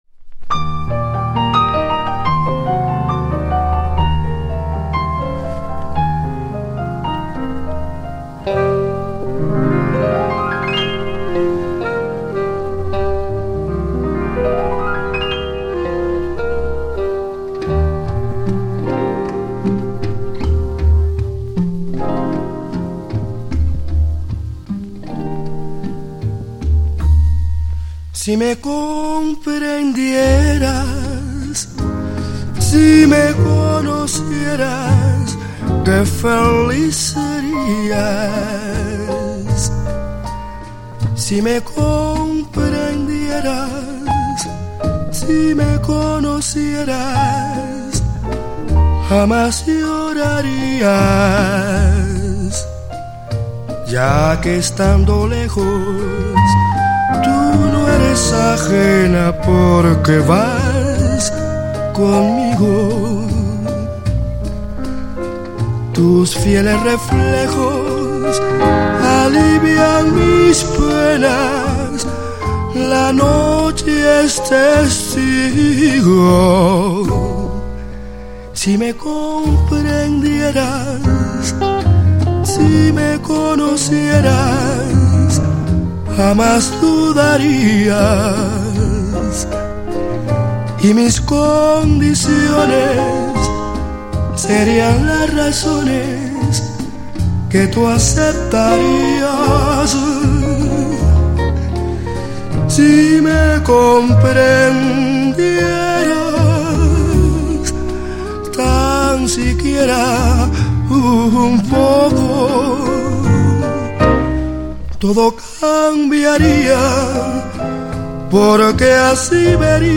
ラテン的ダンディズムの極致、フィーリンの“キング”と渾名された男が５０年代後半に残した傑作！
独特なスモーキー・ヴォイスで綴る珠玉のフィーリン、ここに極まっています。